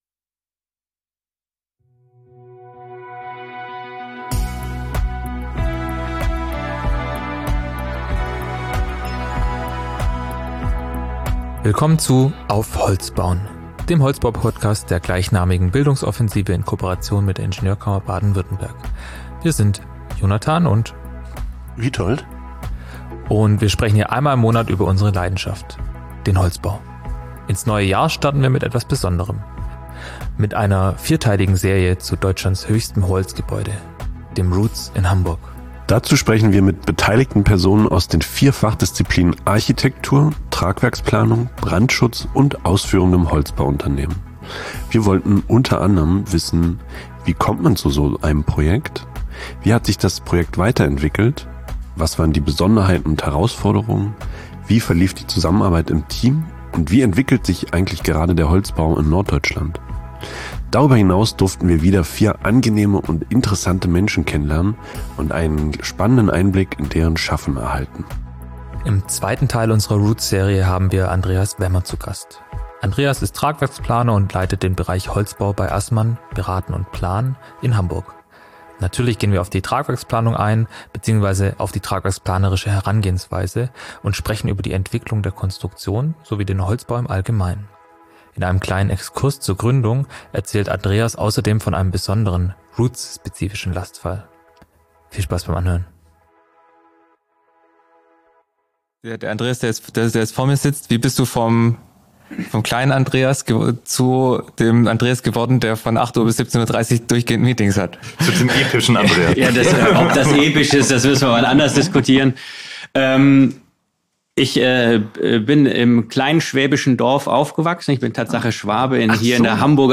In dieser sprechen wir mit beteiligten Personen aus den vier Fachdisziplinen Architektur, Tragwerksplanung, Brandschutz und ausführendem Holzbauun...